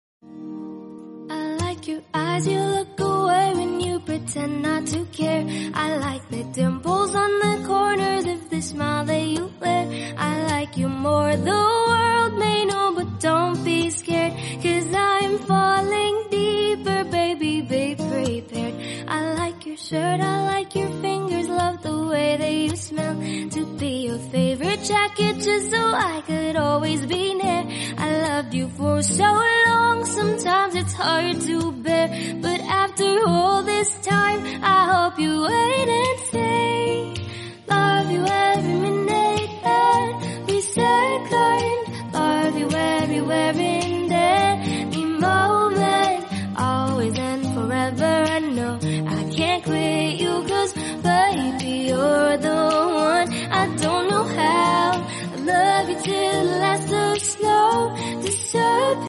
karaoke duet